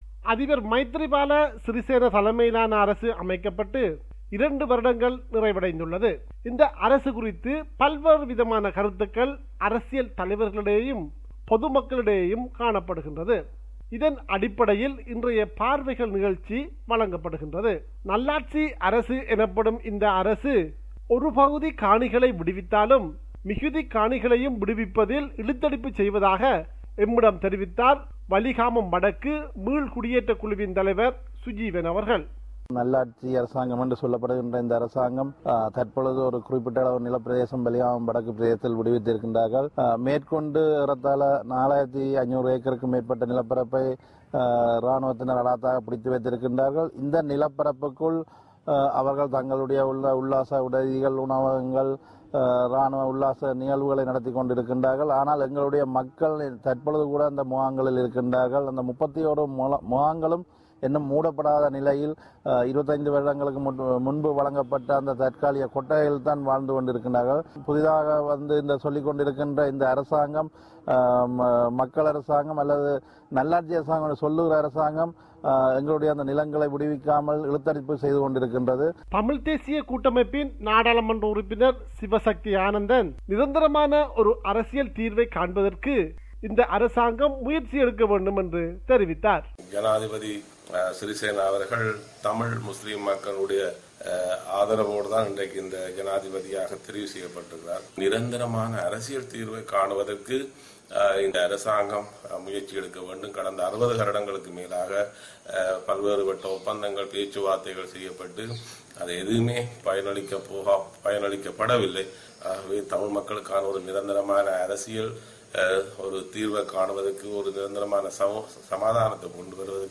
compiled a report focusing on major events/news in North & East